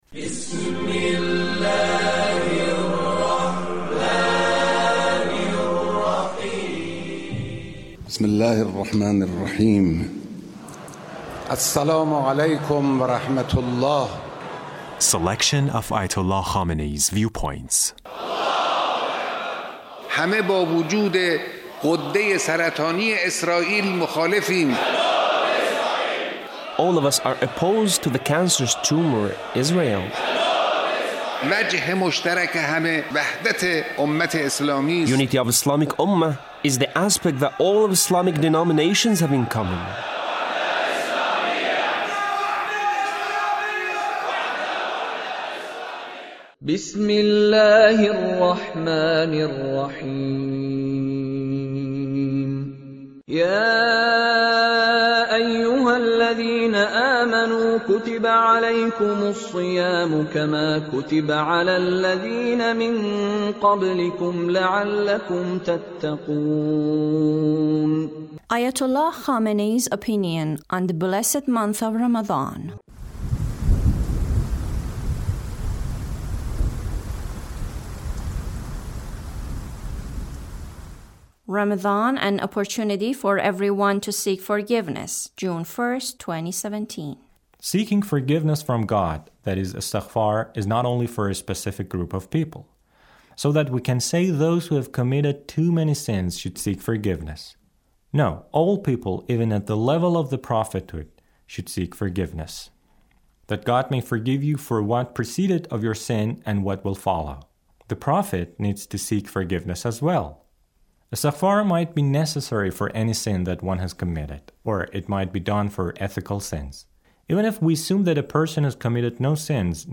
Leader's speech (105)